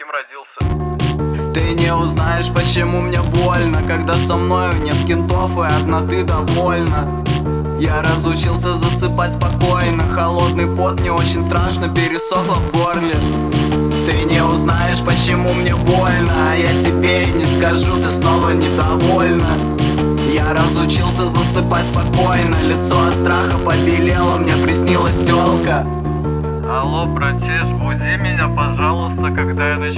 # Alternative